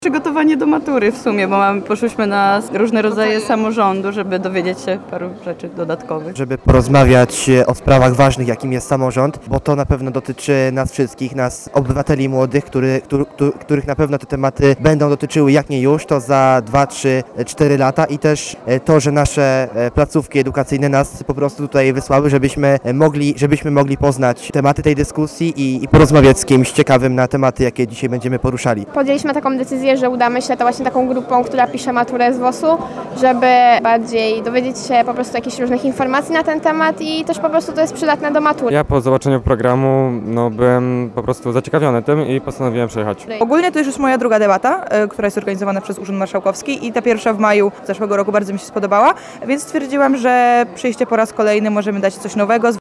Zachęcamy do wysłuchania sondy przeprowadzonej wśród studentów: